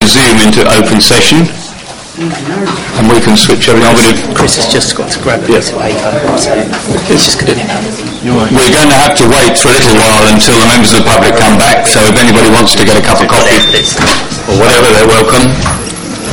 Council (Extraordinary - Planning) meeting audio recordings | Maldon District Council